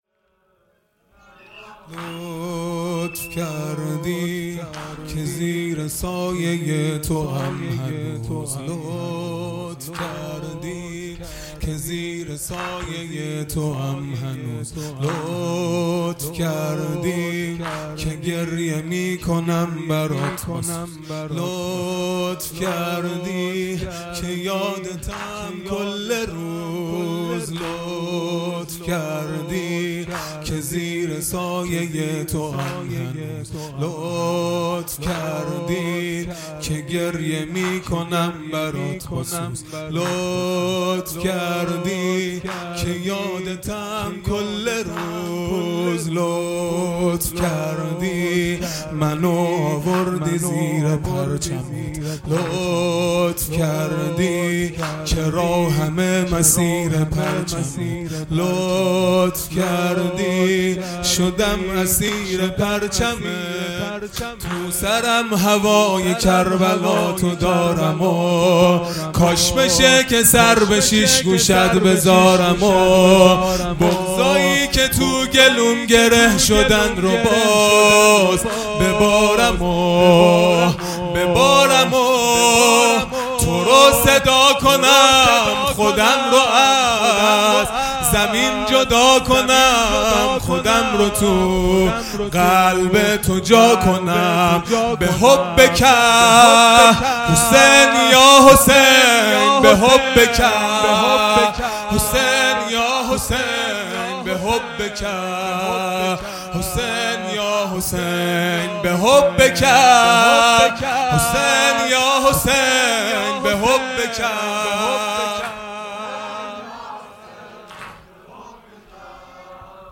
0 0 واحد تند | لطف کردی که زیر سایه تواَم هنوز
دهه اول محرم الحرام ۱۴۴٢ | شب اول